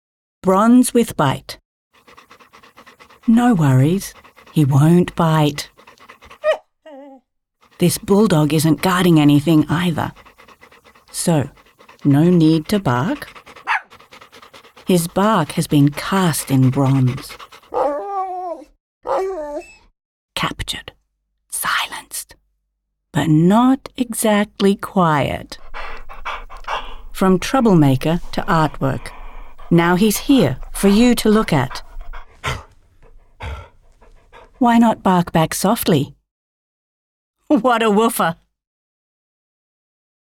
In our KUNST ZUM HÖREN series, art becomes a speaking experience: every work – including the BULL-DOG – has a voice. We have written a tongue-in-cheek audio statement for this sculpture.
Tip: Just listen – or bark along yourself!